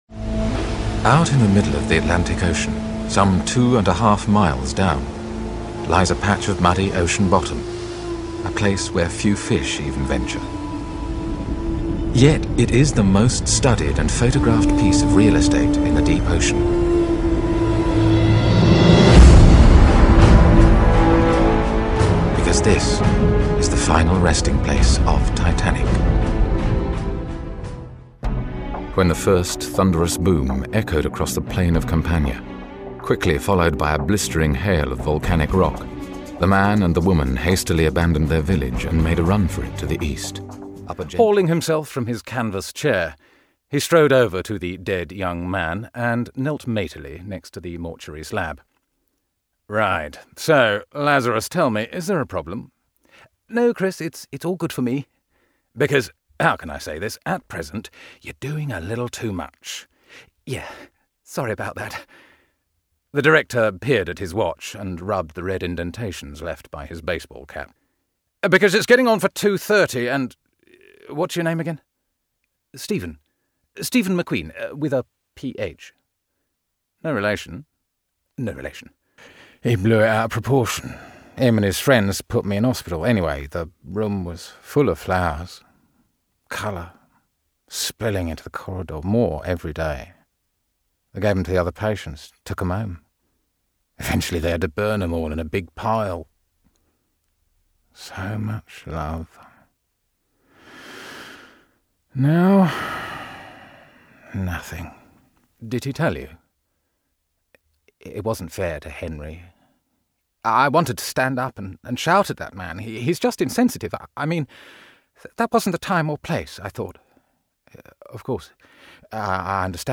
Deep, velvety, epic and sophisticated. RP accent & a great understanding of language
britisch
Sprechprobe: Industrie (Muttersprache):